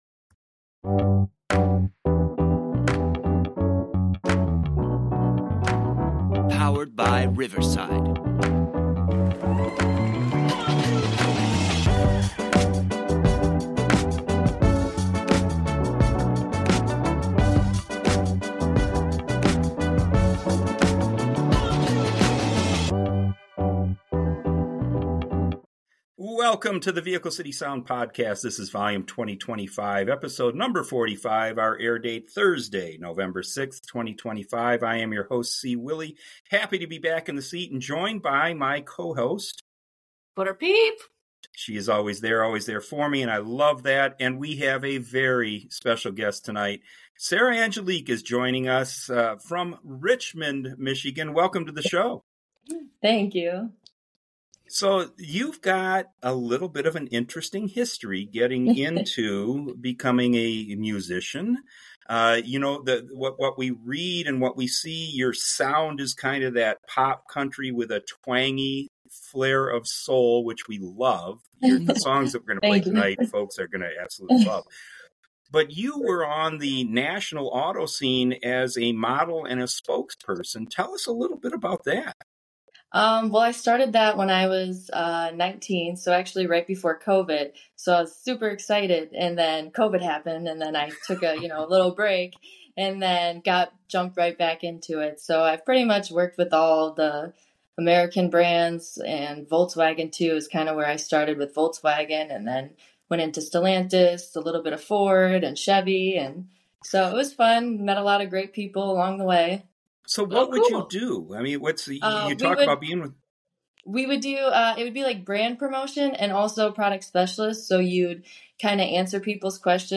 and her bright, polished sounds will definitely make you a fan.&nbsp